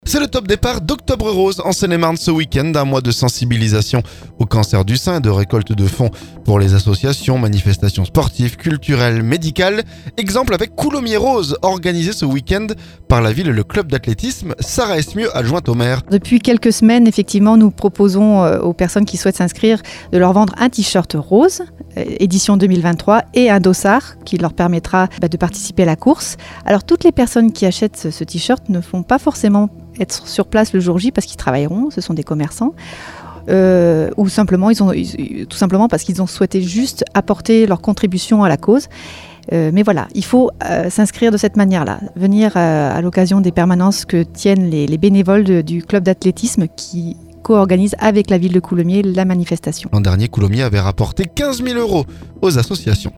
Exemple avec Coulommiers Rose, organisé ce week-end par la ville et le club d'athlétisme. Sarah Esmieu adjointe au maire.